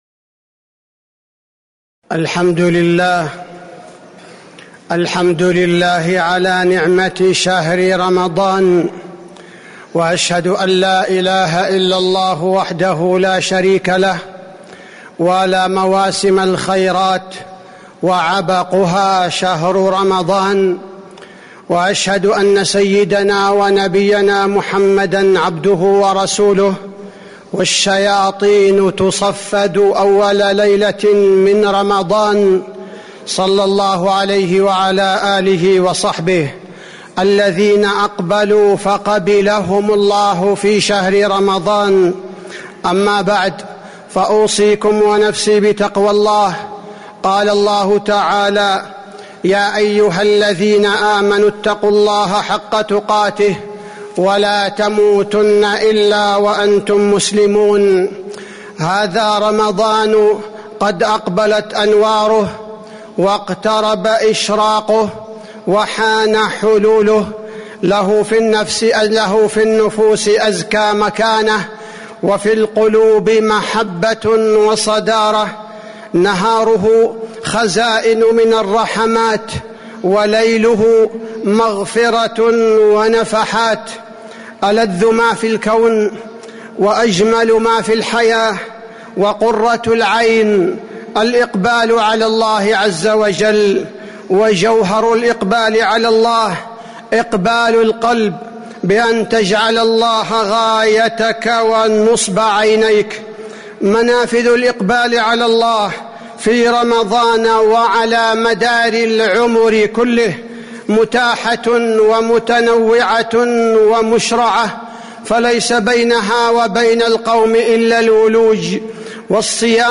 تاريخ النشر ٢٧ شعبان ١٤٤٥ هـ المكان: المسجد النبوي الشيخ: فضيلة الشيخ عبدالباري الثبيتي فضيلة الشيخ عبدالباري الثبيتي رمضان موسم الطاعات